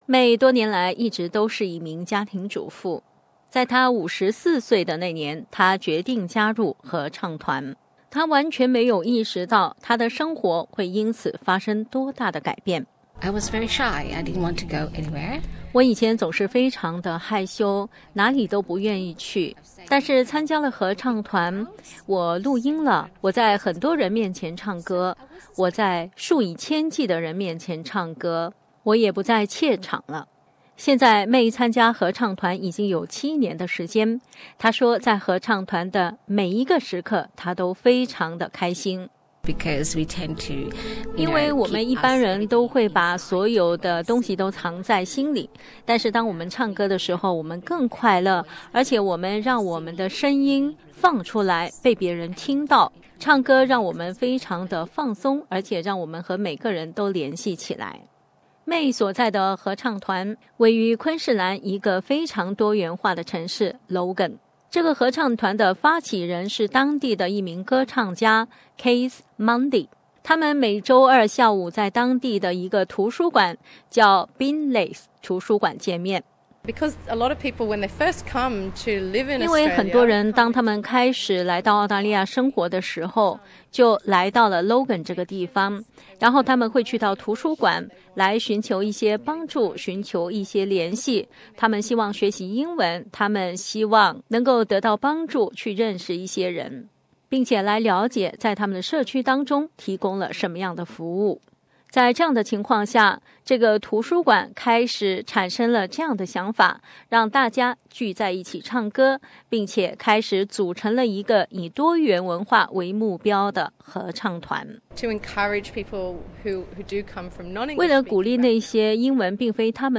不信？就来听听昆士兰一个多元文化老年合唱团里的团员们现身说法：